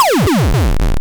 gameover2.wav